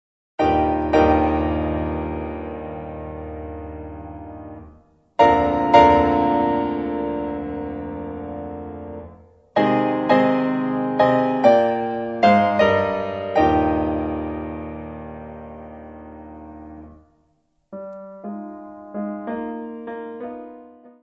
Área:  Música Clássica